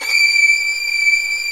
Index of /90_sSampleCDs/Roland LCDP13 String Sections/STR_Violins V/STR_Vls8 Agitato